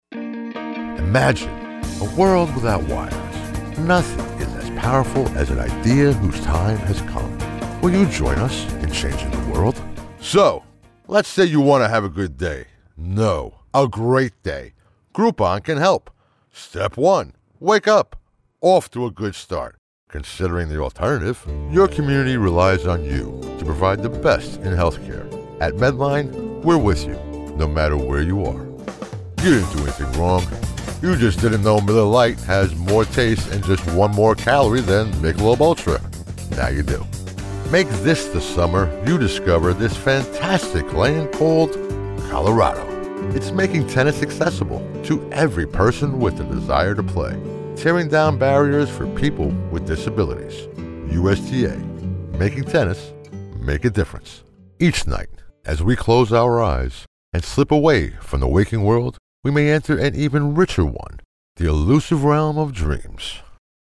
Voice is Deep, Distinctive, and Authoritative, with a Commanding Presence that exudes Confidence and Power.
Radio Commercials